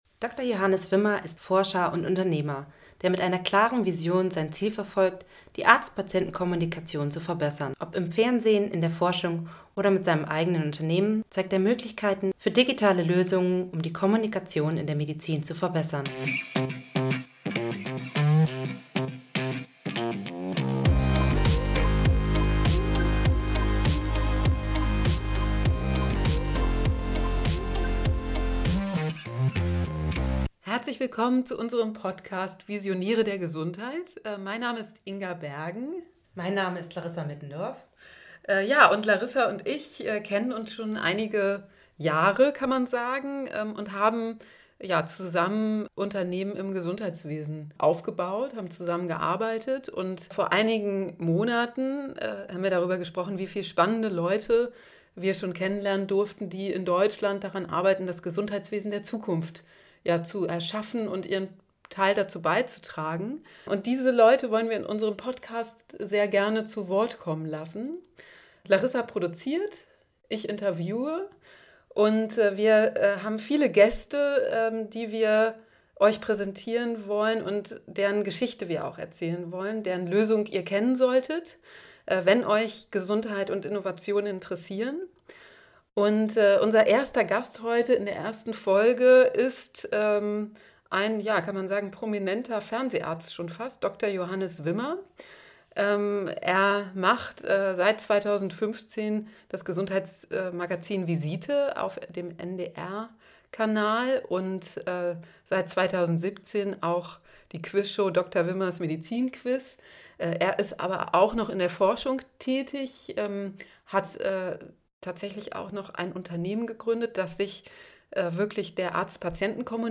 Für die erste Episode haben wir Dr. Johannes Wimmer, einen der bekanntesten Mediziner Deutschlands an seinem Arbeitsplatz in einem großen Berliner Lehrkrankenhaus besucht.